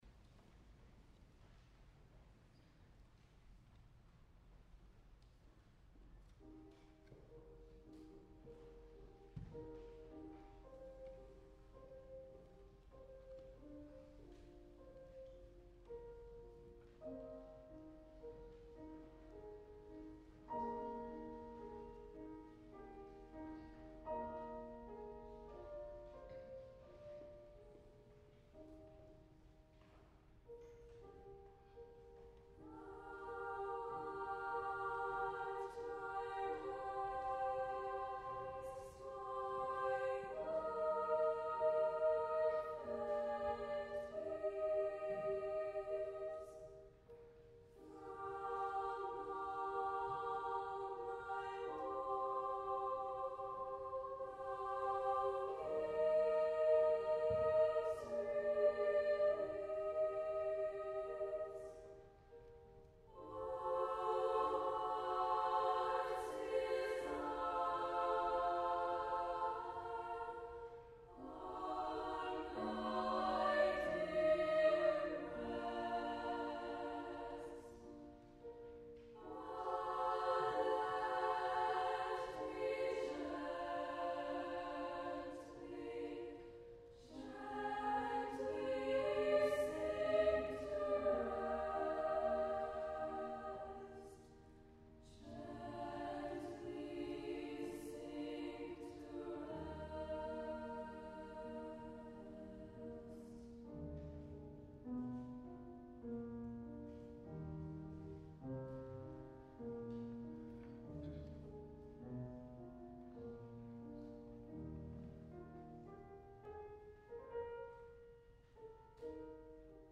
由旧金山女孩合唱团录制和表演